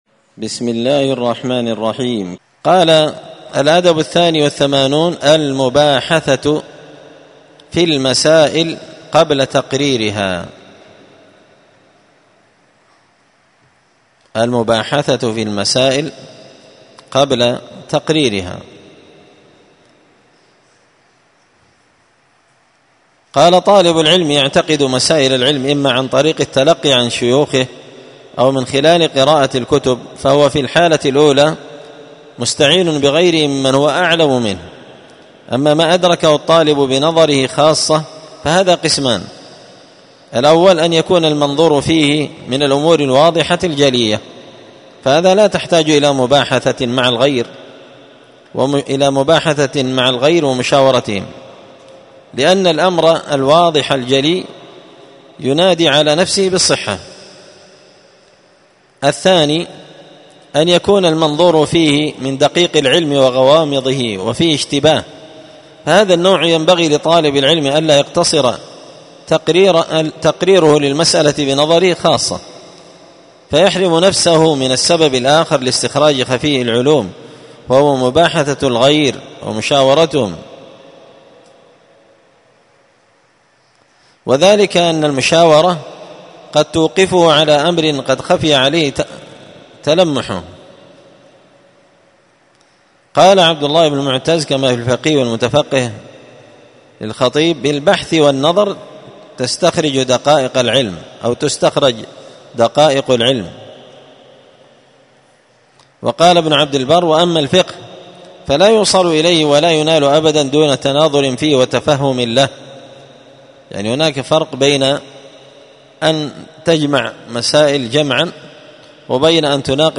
تعليق وتدريس الشيخ الفاضل:
الأربعاء 21 صفر 1445 هــــ | الدروس، النبذ في آداب طالب العلم، دروس الآداب | شارك بتعليقك | 94 المشاهدات